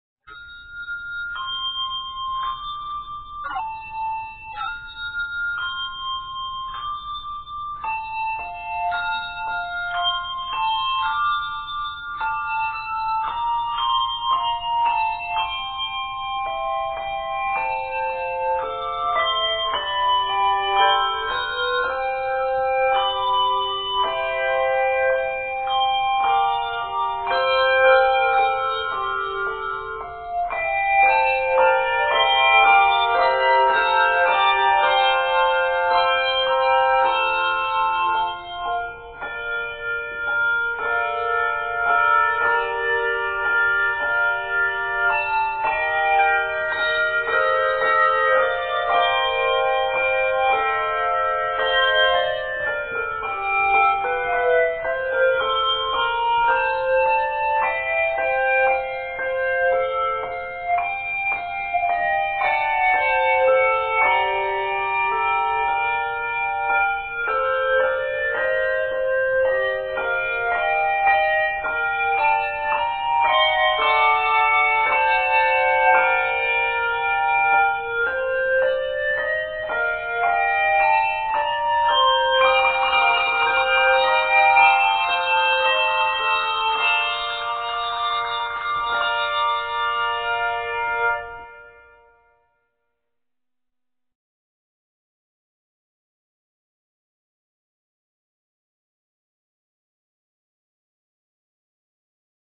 Written for her handchimes in Brazil
two octave arrangement